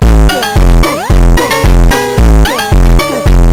killsound_retro.wav